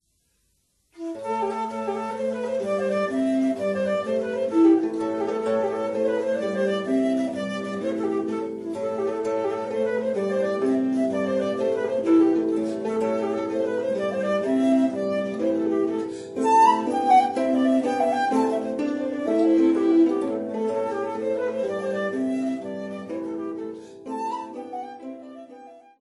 Renaissance, medieval, baroque & folk music